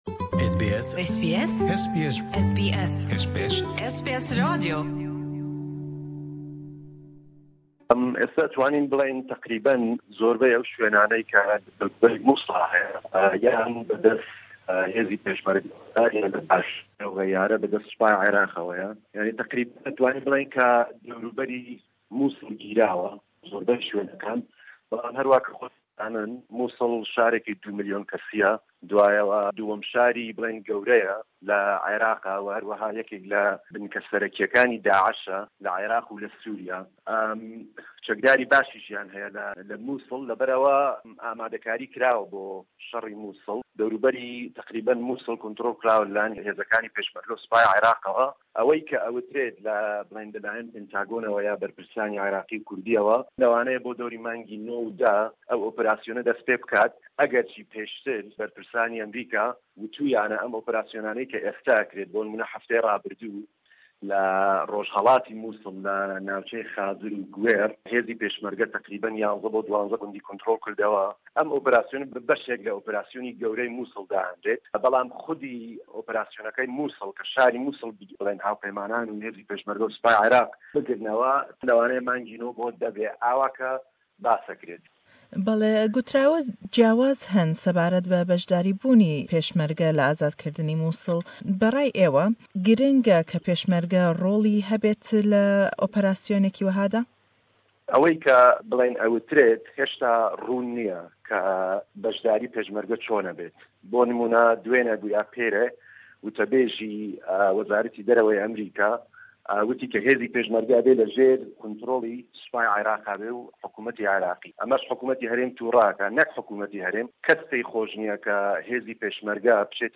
Le em hevpeyvîn e da